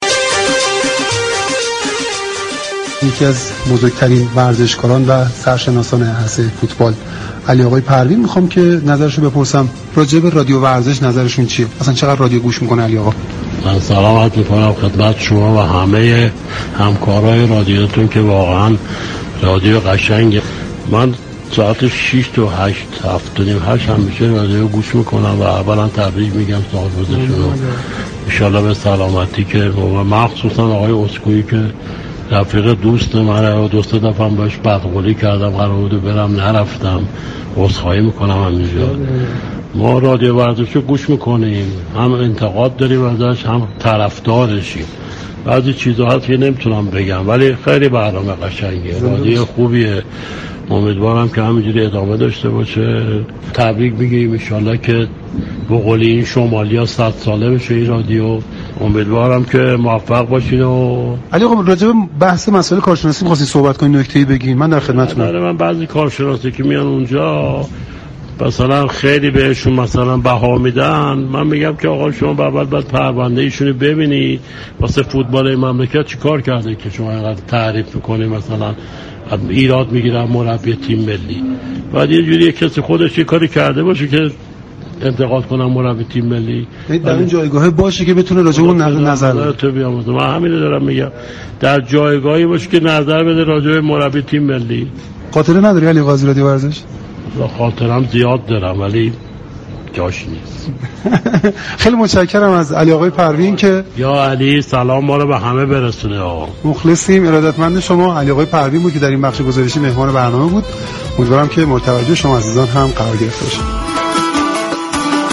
علی پروین، پیشكسوت فوتبال به مناسبت آغاز بیست و دومین سالروز تأسیس رادیو ورزش، میهمان ویژه برنامه